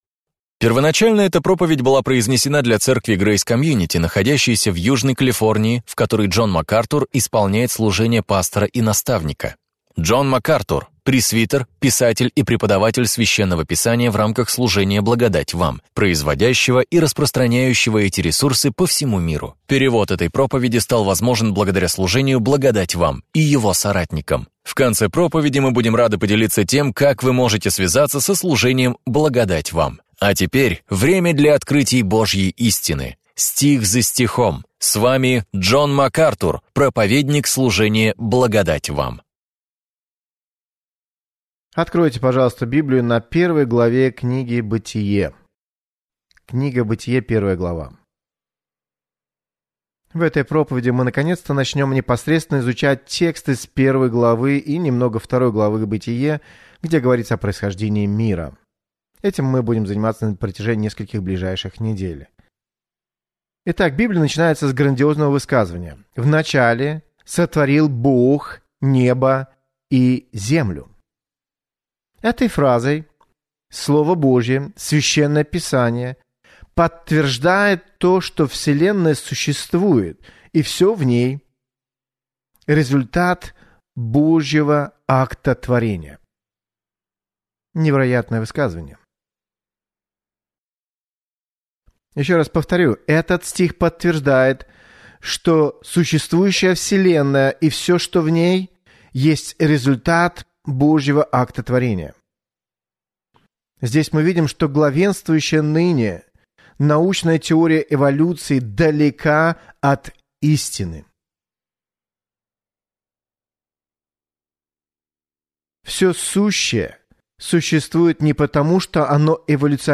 В своей проповеди «Битва за начало» Джон Макартур раскрывает суть этих споров